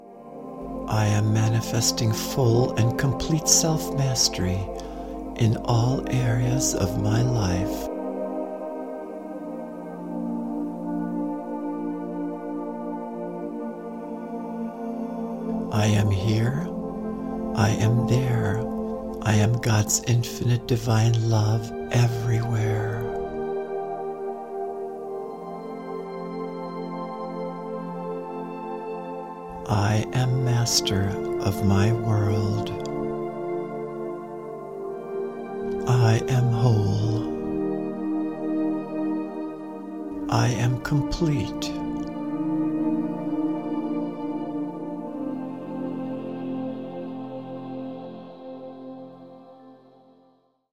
Recorded in 432 Hz Solfeggio for healing and grounding.